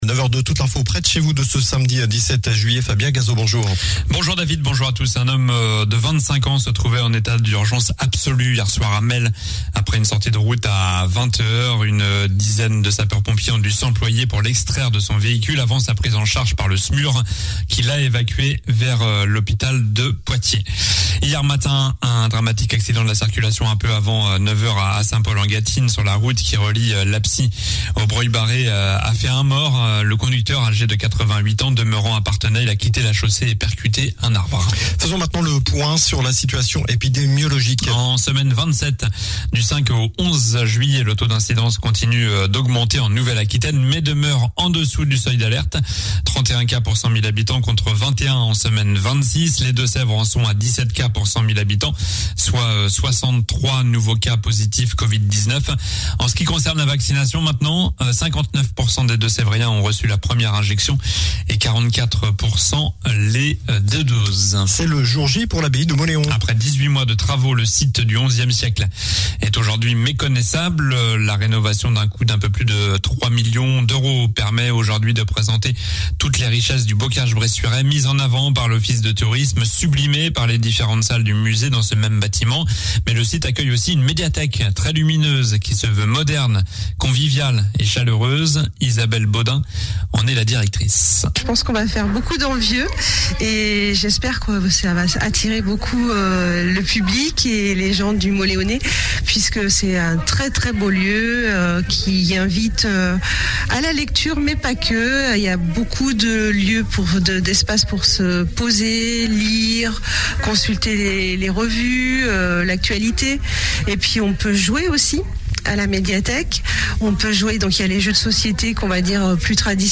Journal du samedi 17 juillet (matin)